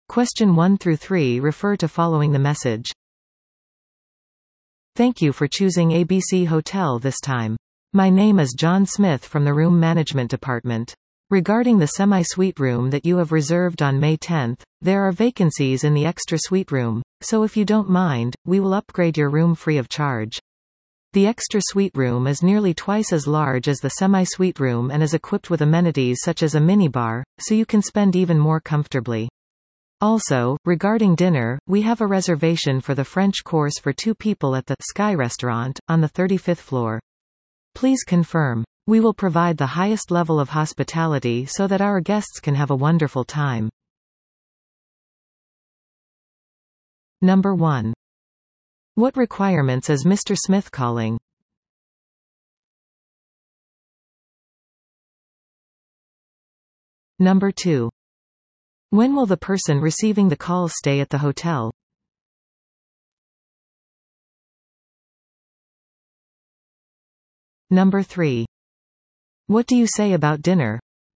TOEIC　PART４のリスニング問題です。